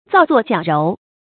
造作矫揉 zào zuò jiǎo róu
造作矫揉发音
成语注音 ㄗㄠˋ ㄗㄨㄛˋ ㄐㄧㄠˇ ㄖㄡˊ